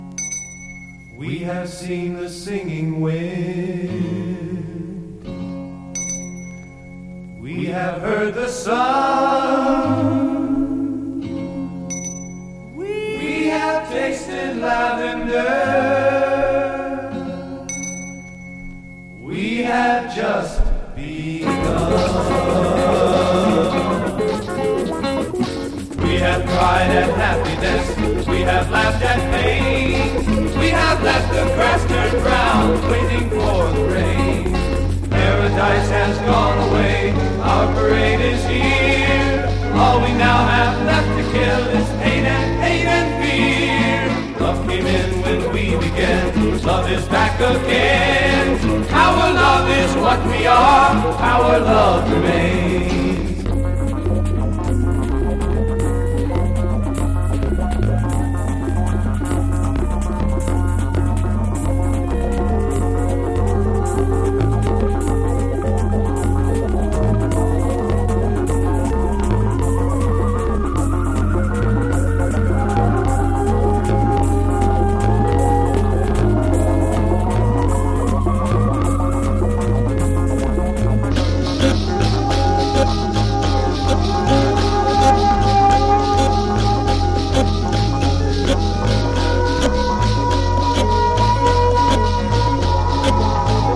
電子音楽コラージュ/ミュージック・コンクレート傑作をたっぷり収録！